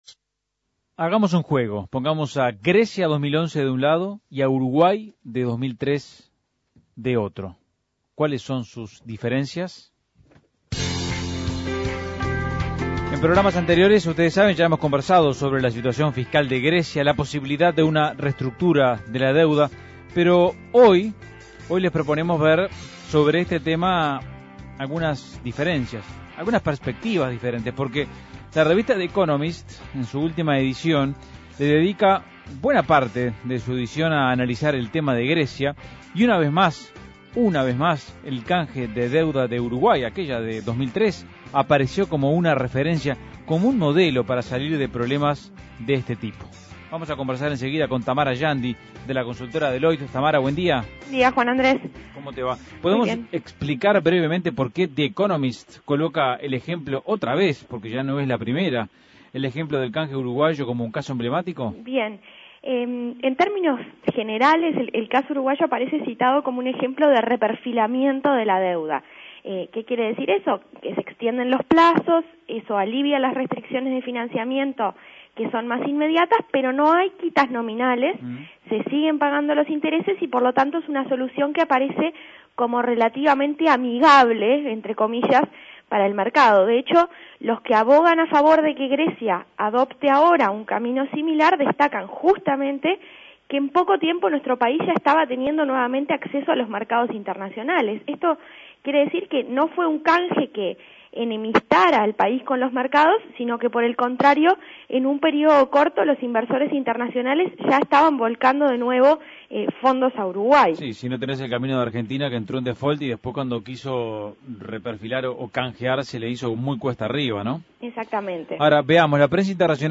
análisis de la economista